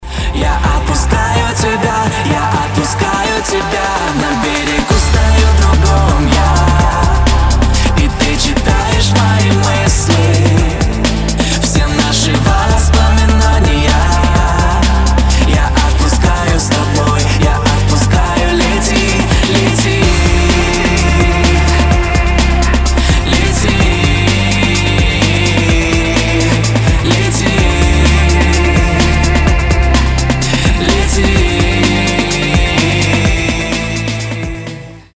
• Качество: 320, Stereo
поп
мужской вокал
громкие
грустные